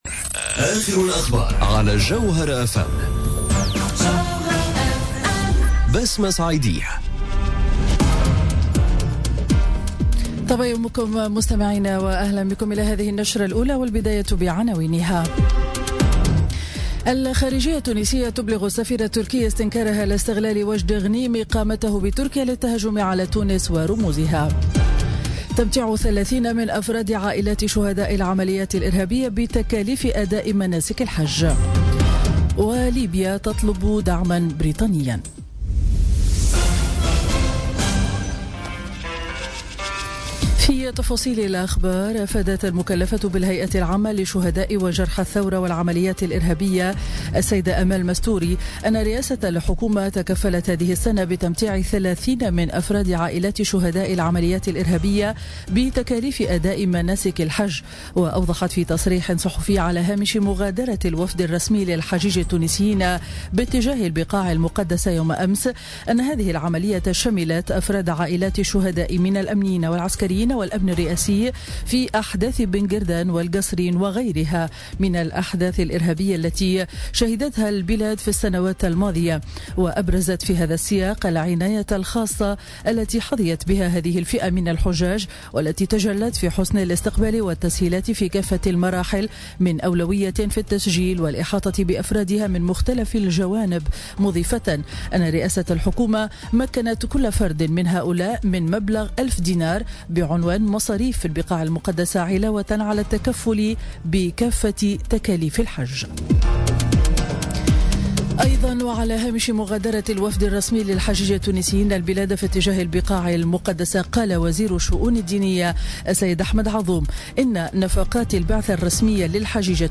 نشرة أخبار السابعة صباحا ليوم الخميس 24 أوت 2017